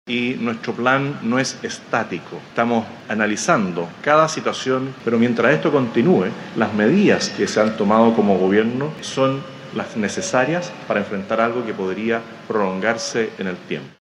De esta manera, el Presidente José Antonio Kast señaló que se están evaluando otras ayudas, dejando abierta la posibilidad de incorporar nuevas acciones en el futuro.